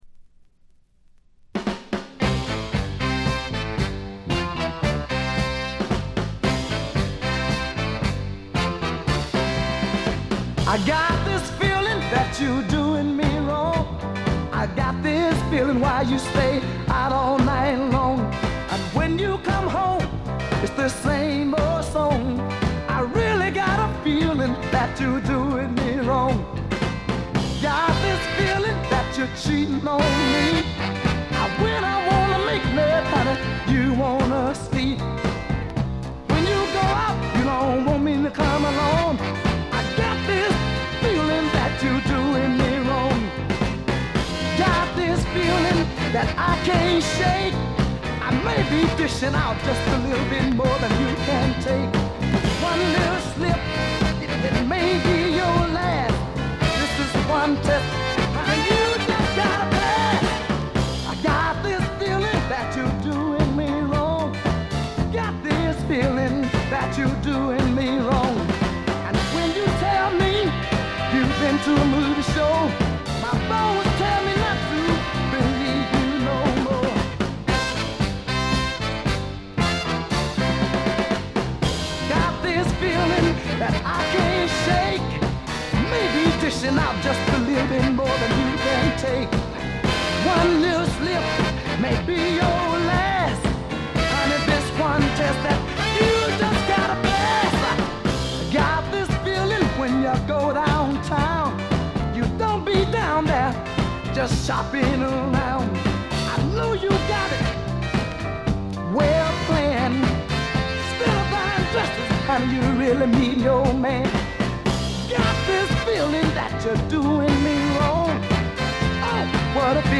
ところどころでチリプチ。
いうまでもなく栄光のサザンソウル伝説の一枚です。
試聴曲は現品からの取り込み音源です。